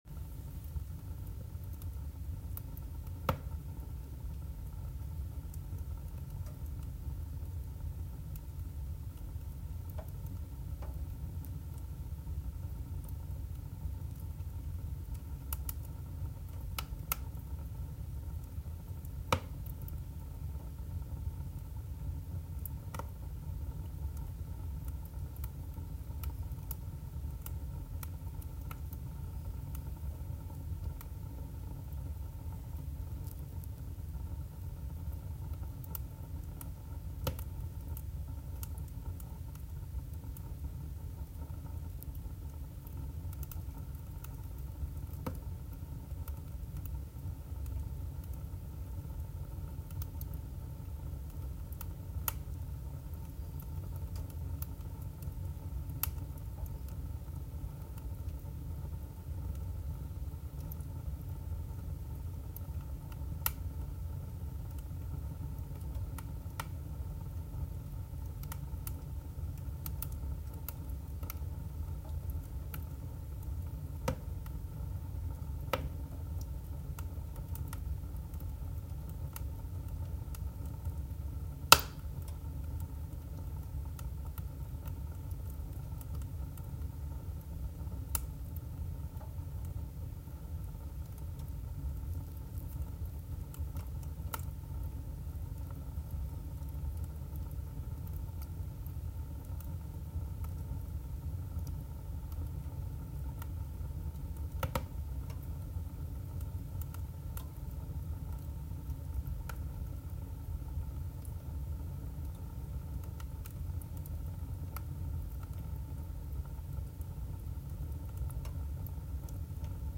Kaminfeuer (MP3)
Kaminfeuer.mp3